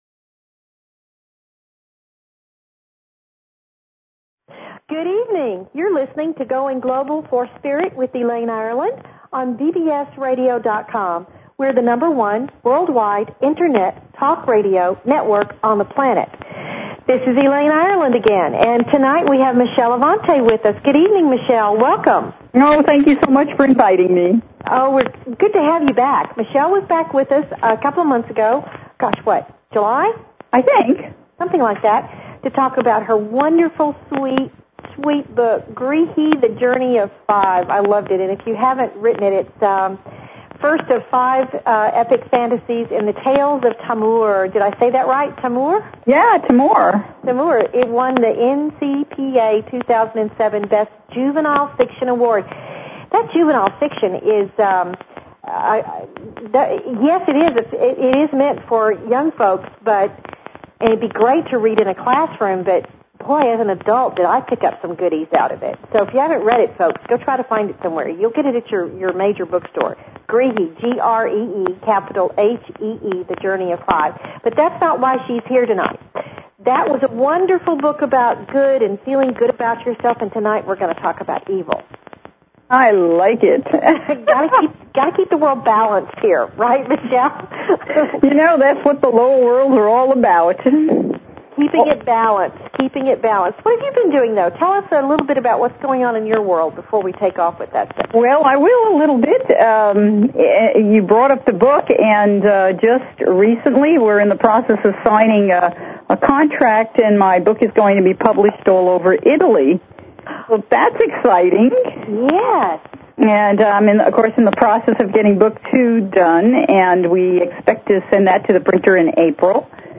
Talk Show Episode, Audio Podcast, Going_Global_for_Spirit and Courtesy of BBS Radio on , show guests , about , categorized as
A variety of guests will be here to teach and share their wonders with you. They invite you to call in with your questions and comments about everything metaphysical and spiritual!"